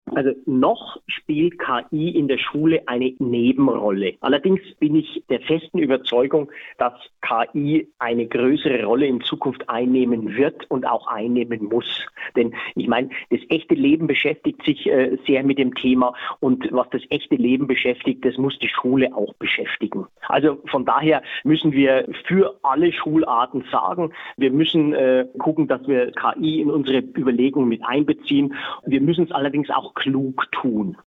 Interview: Künstliche Intelligenz an Schulen - PRIMATON